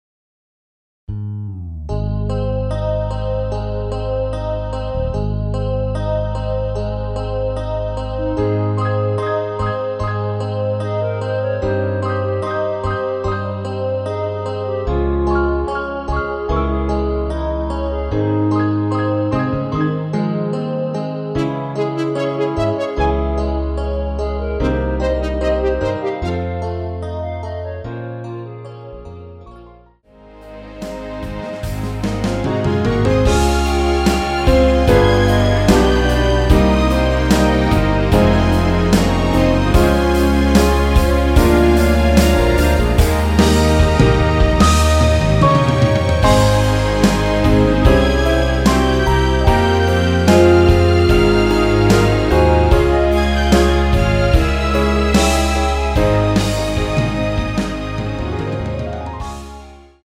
엔딩이 페이드 아웃이라 라이브하기 좋게 엔딩을 만들어 놓았습니다.
원키에서(-2)내린 멜로디 포함된 MR입니다.
Ab
앞부분30초, 뒷부분30초씩 편집해서 올려 드리고 있습니다.
(멜로디 MR)은 가이드 멜로디가 포함된 MR 입니다.